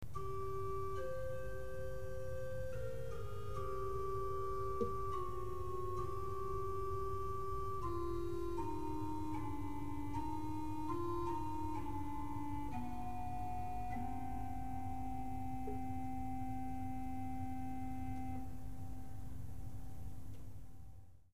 Kwintadena, Quintadena, Quintaton
Organowy głos składający się z krytych piszczałek o wąskiej menzurze. W dźwięku słychać wyraźnie udział trzeciego tonu alikwotowego, czyli kwinty (brzmieniem głos ów zbliża się nieco do klarnetu).